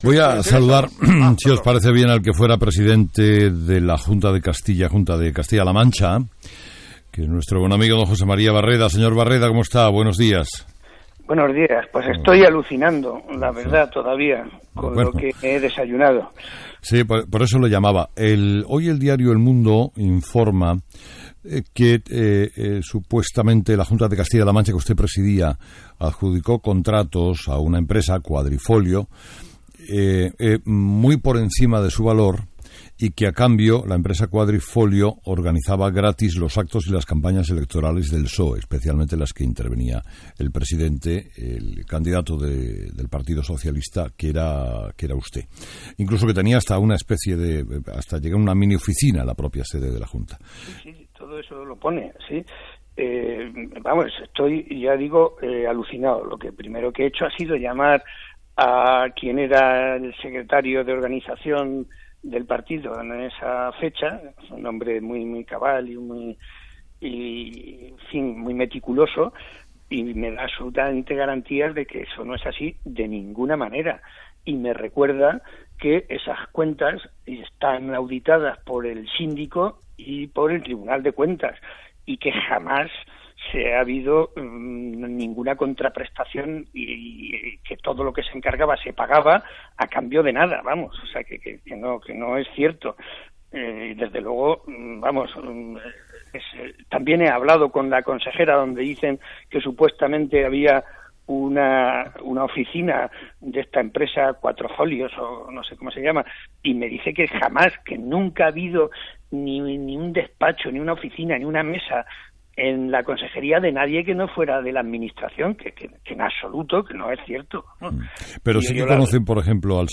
Entrevistado: "José María Barreda"